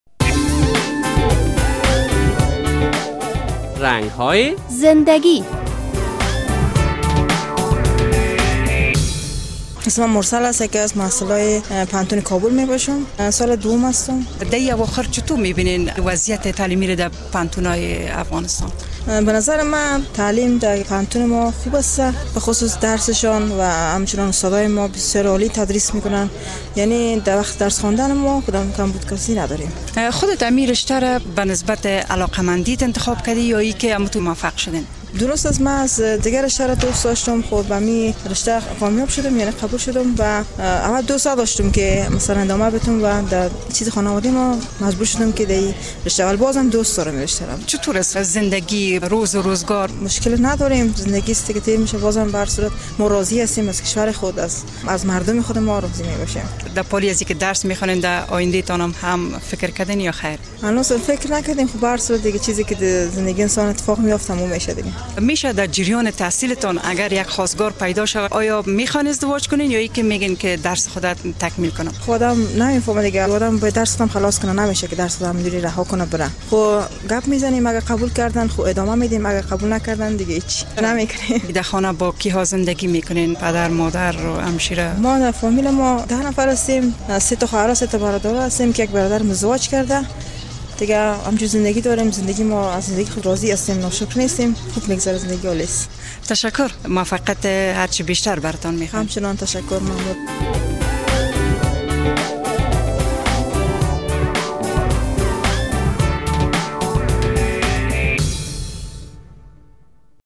در برنامهء امروز رنگ های زنده گی در مورد وضعیت تعلیمی در پوهنتون های افغانستان با یک تن از محصلین پوهنتو کابل مصاحبهء صورت گرفته که با کلیک روی لینک زیر می توانید آن را بشنوید: